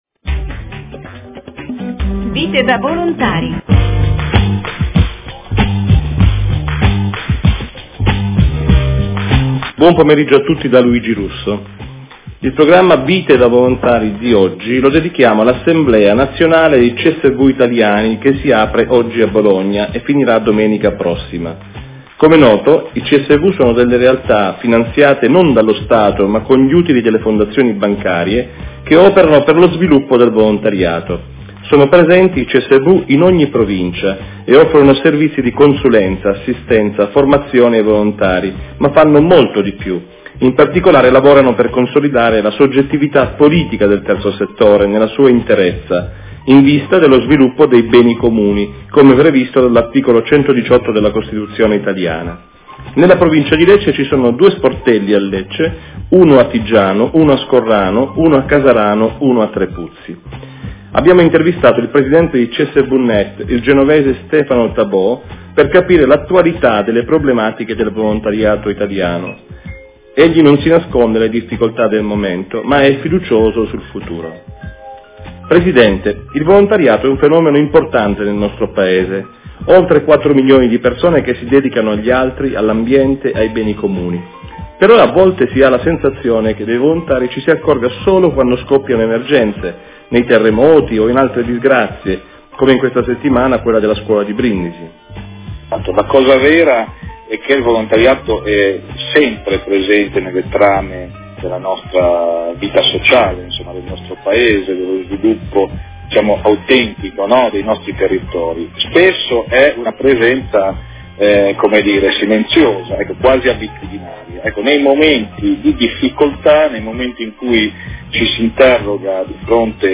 In un'intervista all'emittente Mondoradio